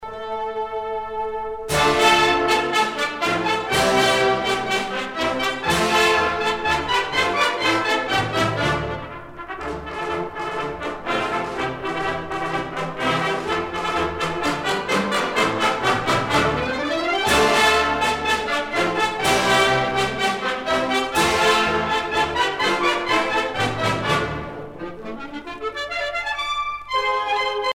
Chanson à virer
Pièce musicale éditée